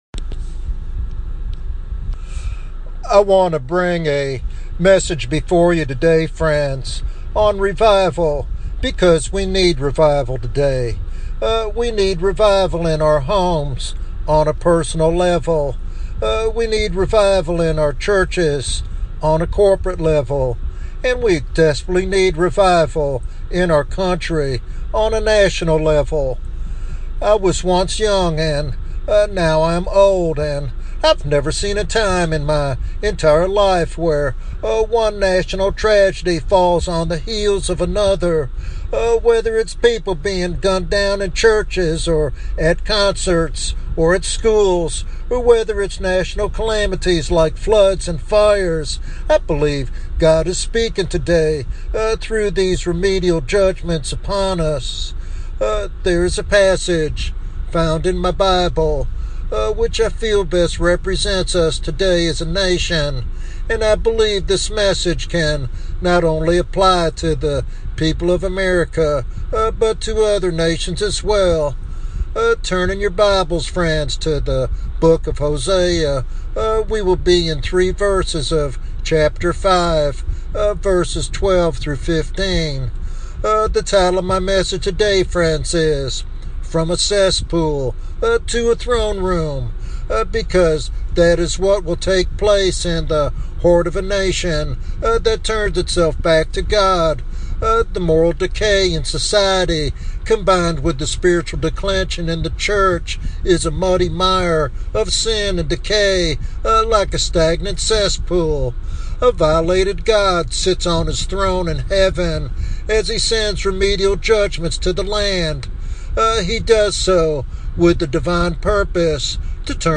This sermon is a powerful call to turn from sin and experience God's transformative grace.